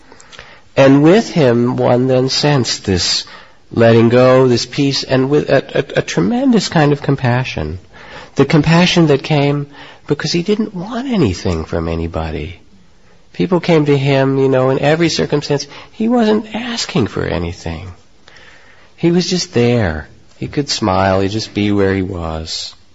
24. The compassion that came because he didn’t want anything from anybody. Reflection by Jack Kornfield.